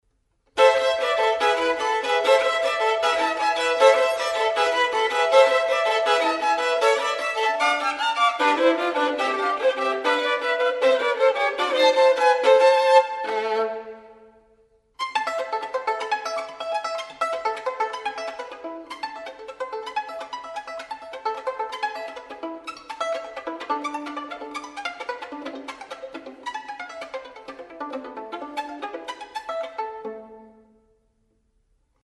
【所屬類別】 XRCD唱片　　古典音樂